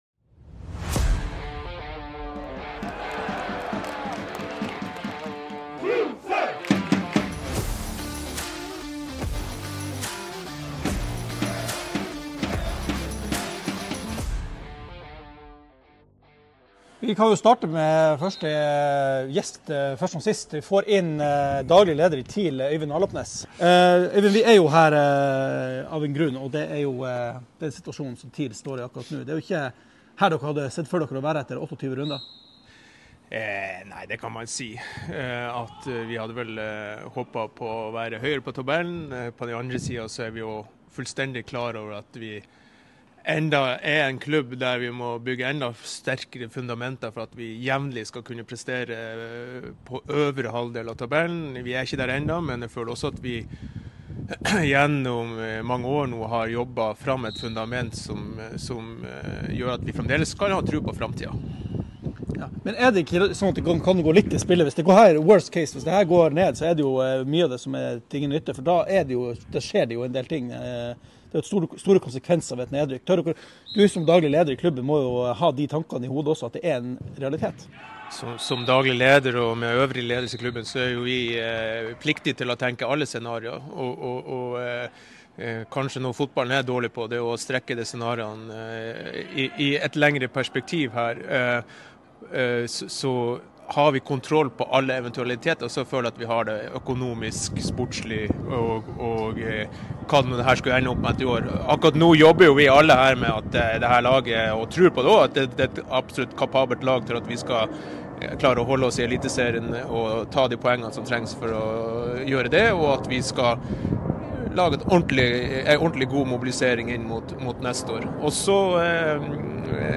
Tromsøball - #65 Ekstrasending: «Tromsøball» flyttet studioet til Alfheim - jakter svar på TIL-krisen